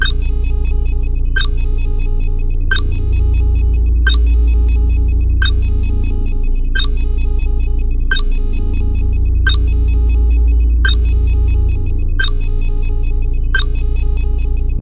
dronebay.ogg